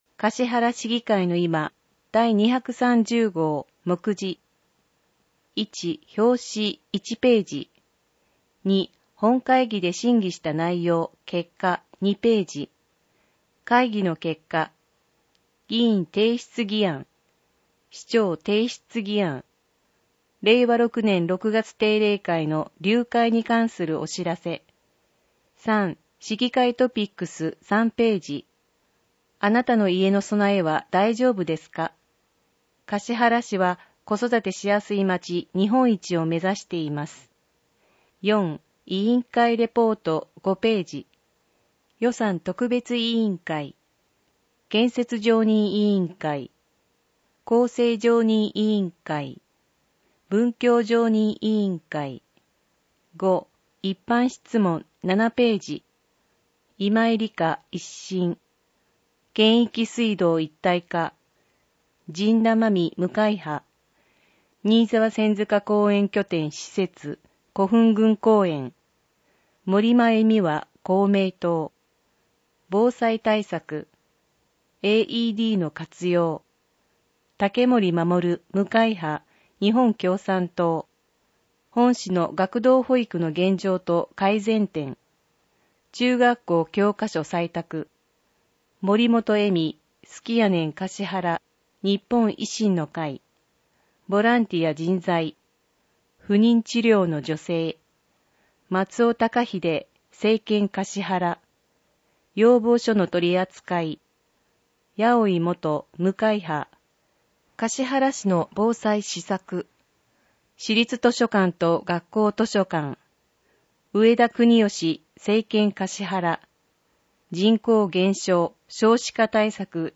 音訳データ
かしはら市議会のいま230号 (PDFファイル: 5.3MB) 音訳データ かしはら市議会のいま第230号の音訳をお聞きいただけます。 音訳データは、音訳グループ「声のしおり」の皆さんが音訳されたものを使用しています。